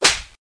打耳光.mp3